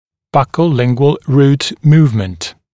[ˌbʌkəu’lɪŋgwəl ruːt ‘muːvmənt][ˌбакоу’лингуэл ру:т ‘му:вмэнт]перемещение корня (корней) в щечно-язычном направлении